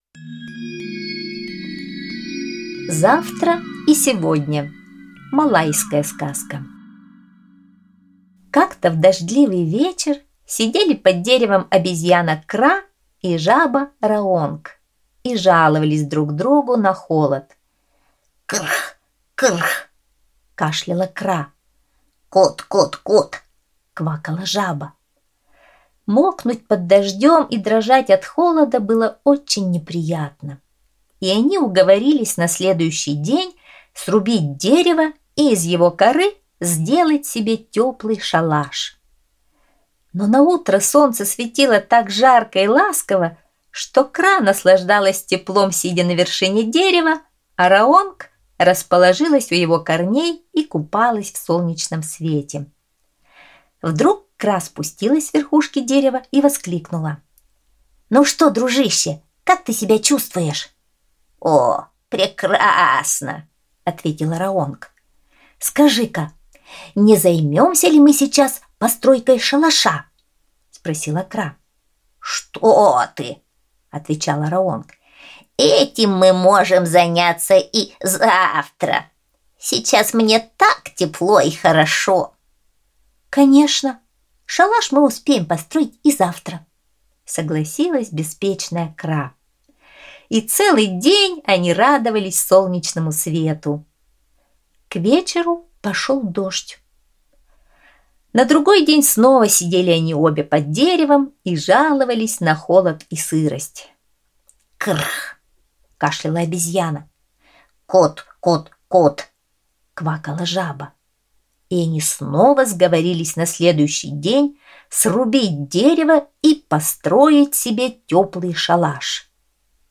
Малайская аудиосказка